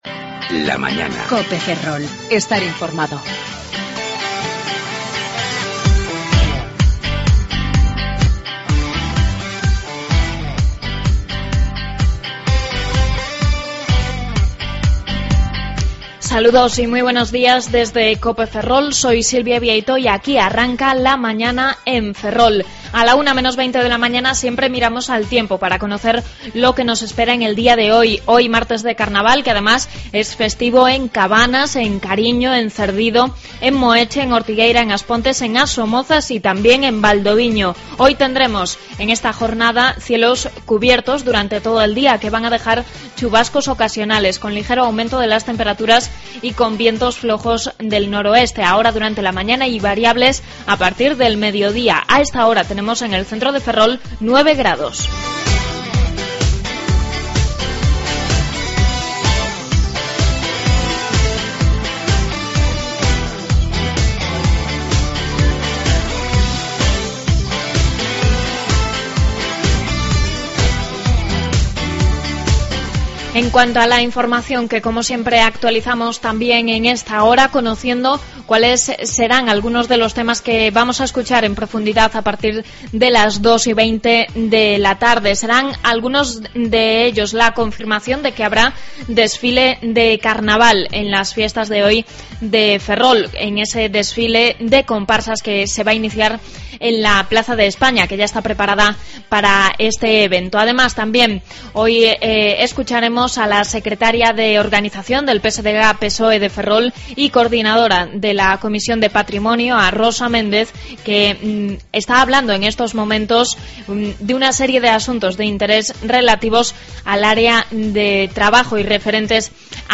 AUDIO: Avances informativos y contenidos de Ferrol, Eume y Ortegal.